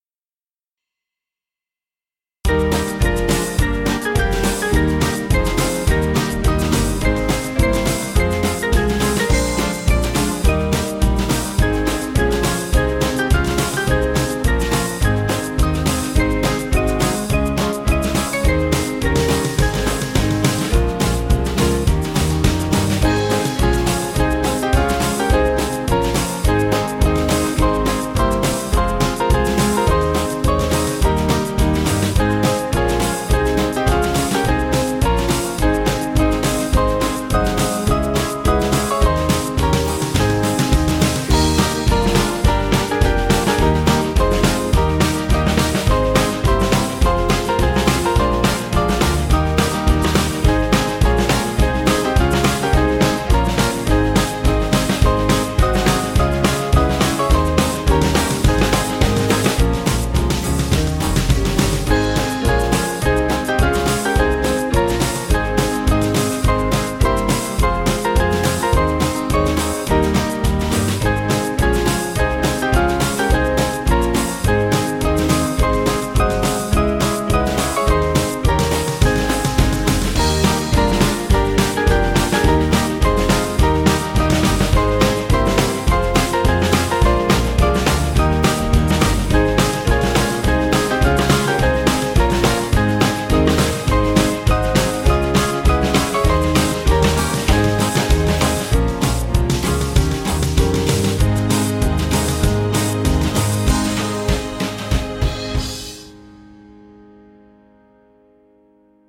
Small Band
(CM)   2/Ab 522.6kb